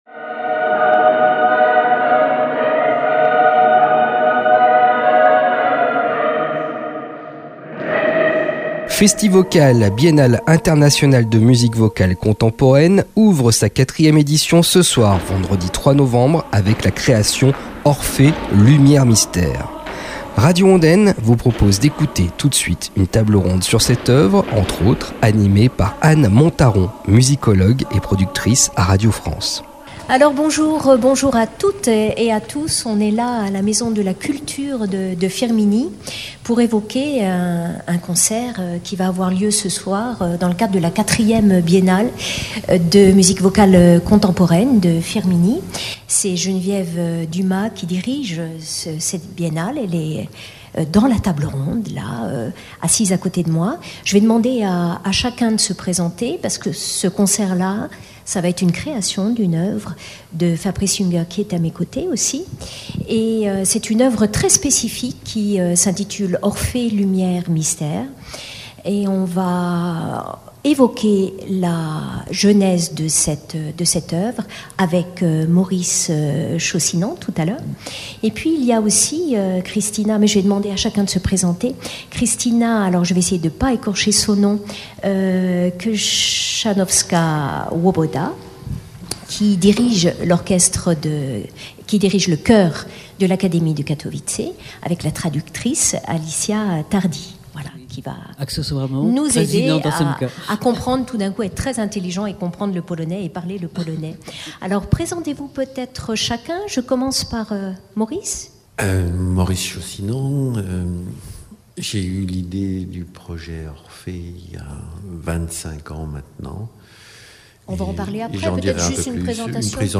FESTYVOCAL, table ronde autour de l’œuvre Orphée/Lumière [Mystères] – Radio Ondaine 90.9 FM